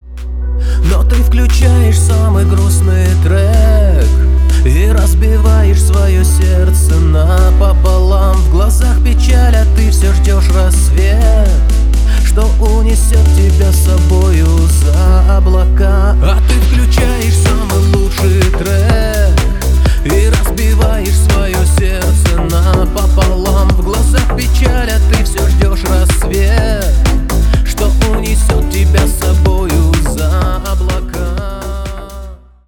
Поп Музыка
клубные